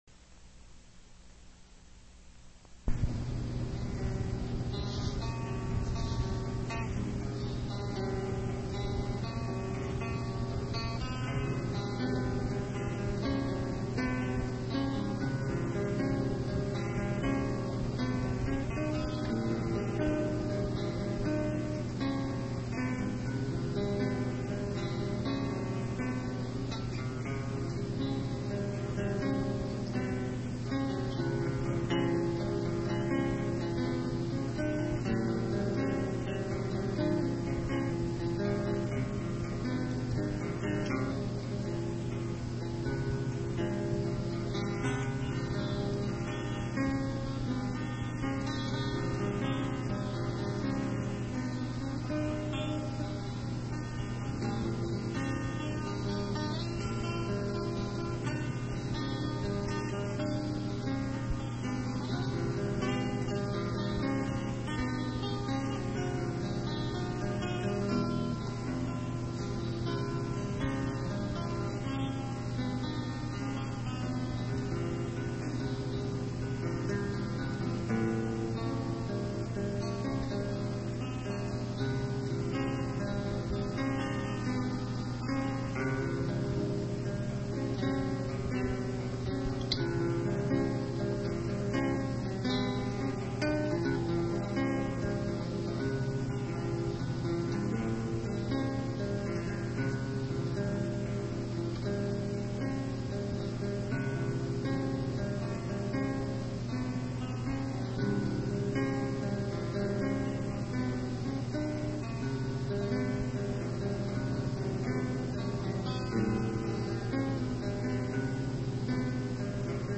on guitar
a little acoustic number